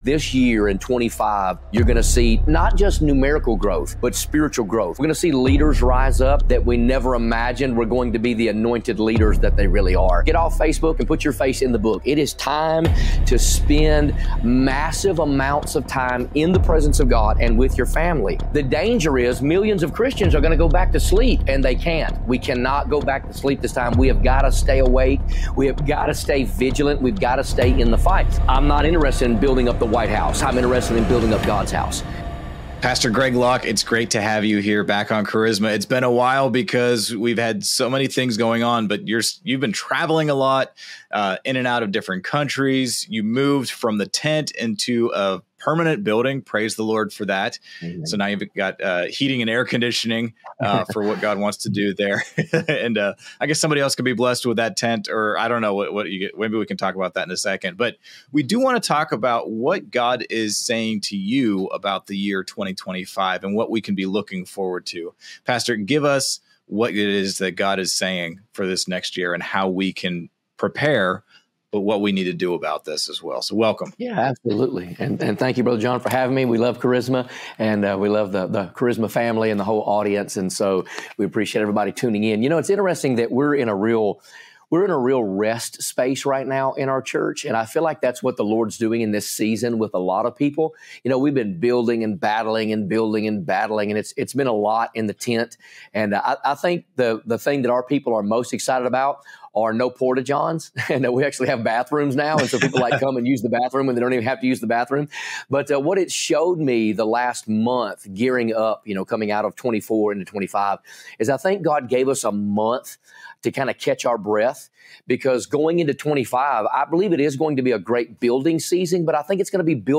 In this insightful interview